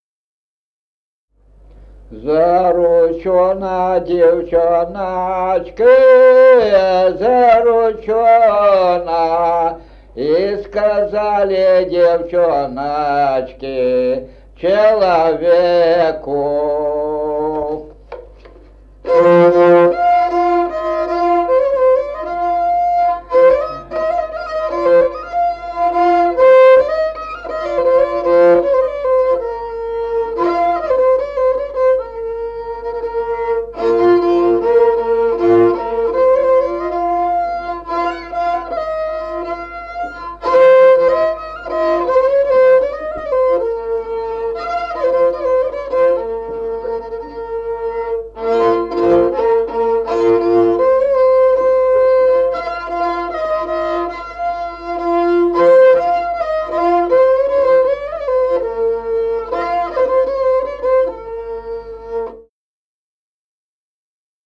Музыкальный фольклор села Мишковка «Заручёна девчоночка», репертуар скрипача.